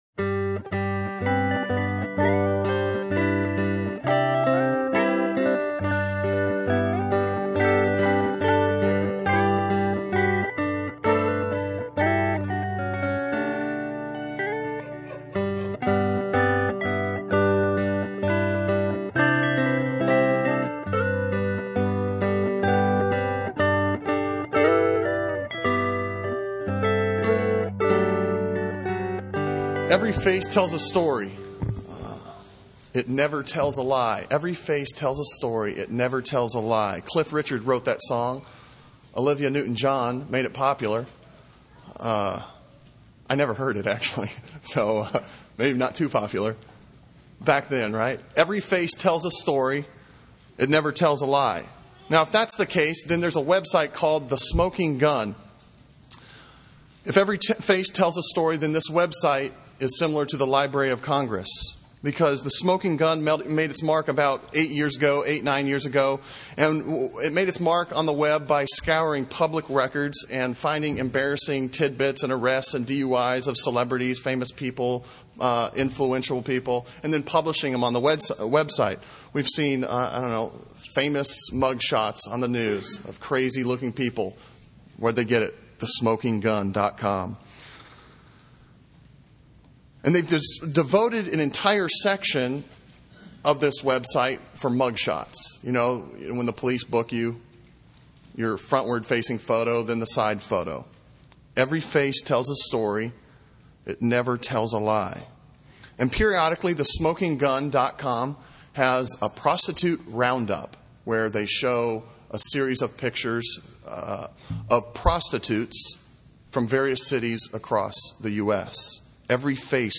Sermon Audio from Sunday